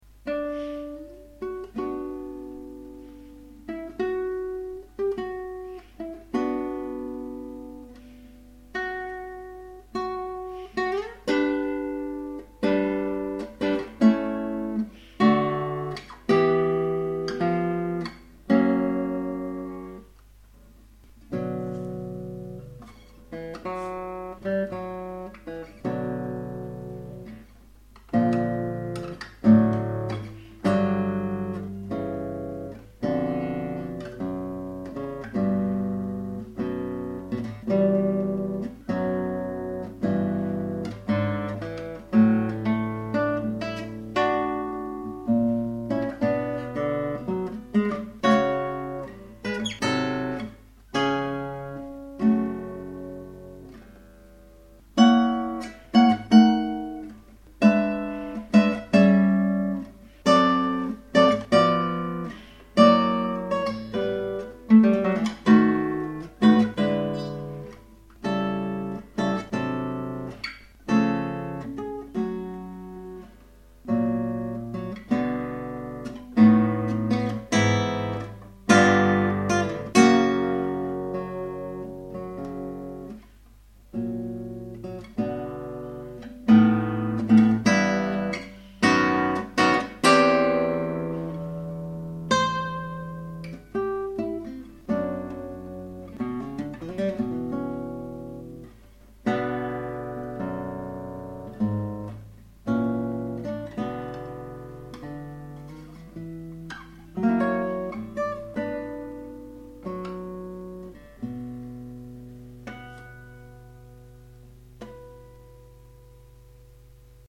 - Guitare Classique
J'en frissonne encore en écrivant ce message... c'est superbe d'émotion et d'intériorisation... la vraie musique, c'est ça !
Cette interprétation montre dans quelle mesure on peut avoir des difficultés avec la technique, et pourtant avoir une musicalité remarquable.